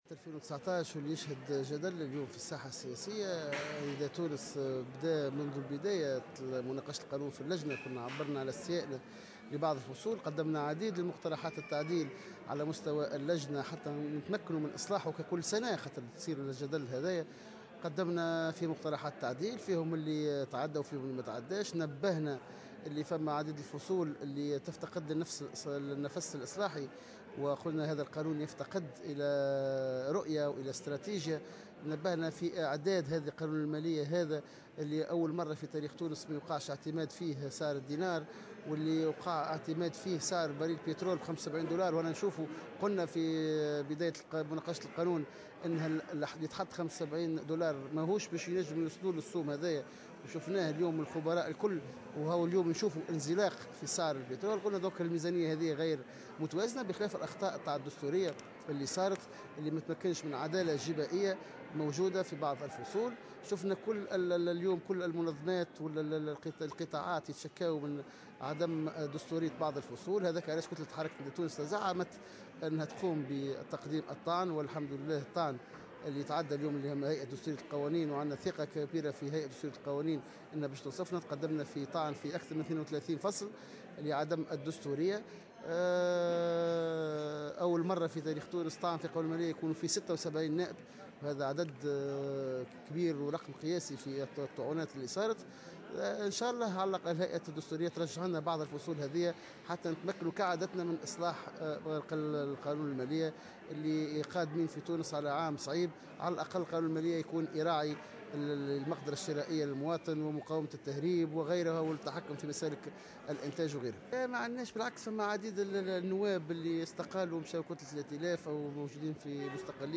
وأكد في تصريح لمراسل "الجوهرة اف ام" على هامش أشغال الندوة الوطنية للمنسقين الجهويين لحركة نداء تونس المنعقدة حاليا في المهدية، أن حركة نداء تونس تزعمت الطعن في قانون المالية 2019 الذي تم تقديمه لهيئة دستورية القوانين والطعن في أكثر من 32 فصلا بقانون المالية الذي تم المصادقة عليه، مضيفا أن 76 نائبا تقدموا بالطعن ولأول مرة في تاريخ تونس أيضا.